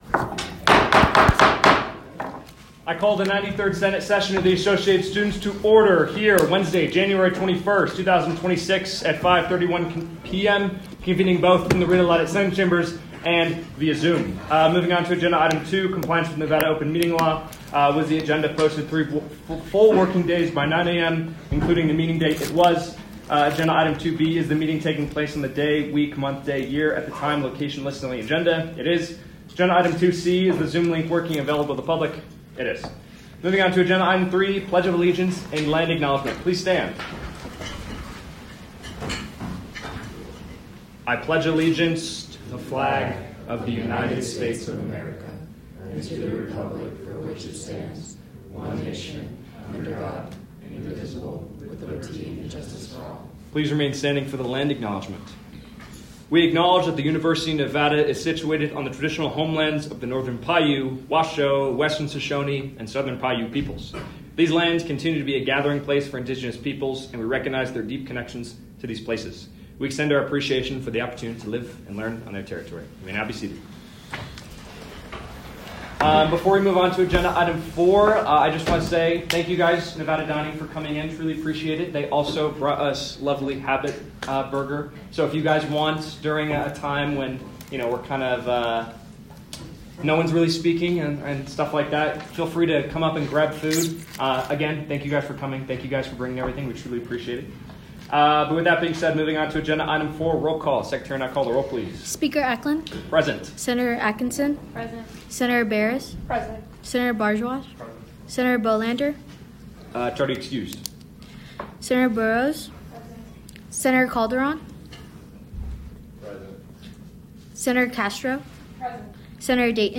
Meeting Type : Senate
Location : Rita Laden Senate Chambers